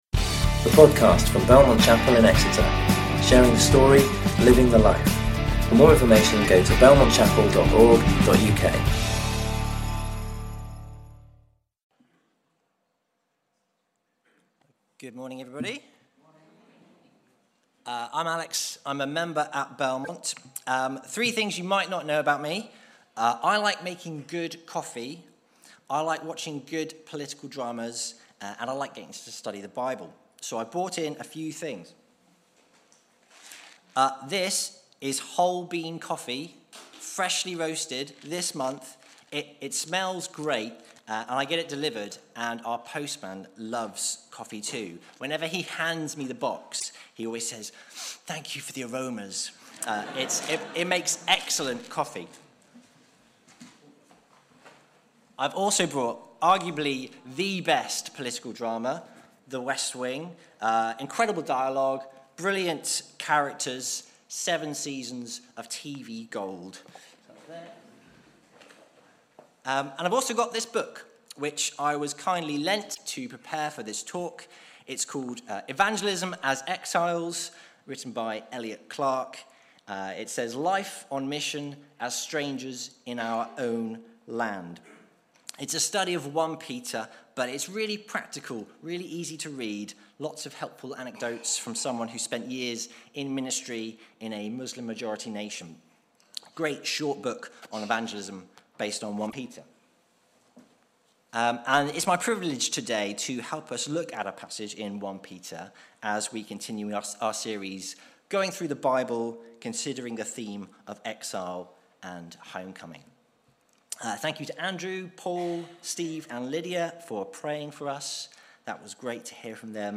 You can listen to or download sermons from Belmont Chapel.